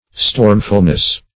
stormfulness - definition of stormfulness - synonyms, pronunciation, spelling from Free Dictionary
-- Storm"ful*ness , n. [1913 Webster]
stormfulness.mp3